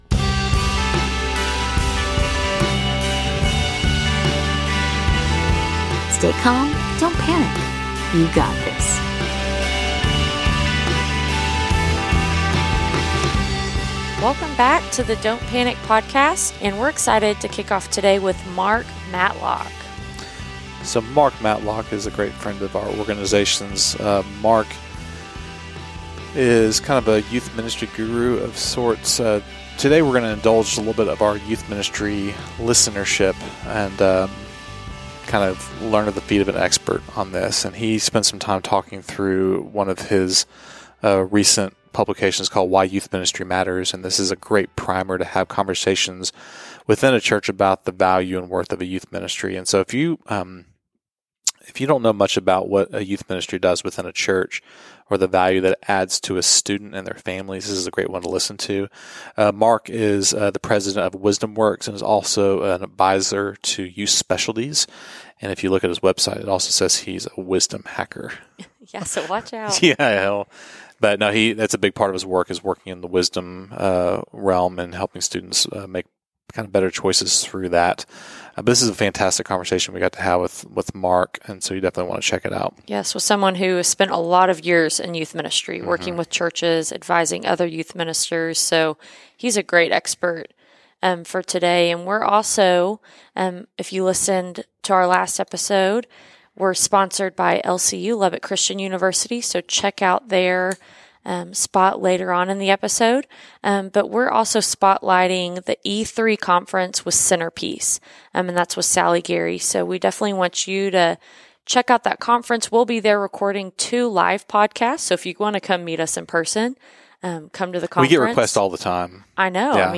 Whether or not you attend church, this is a great conversation about the importance of relationships and a place to belong in the life of a teenager.